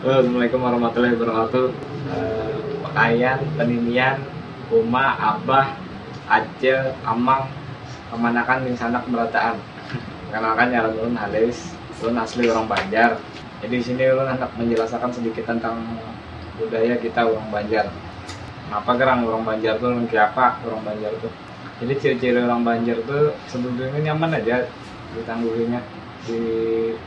It sounds sort of like a languages of the Philippines or Indonesia, but with lots of central vowels.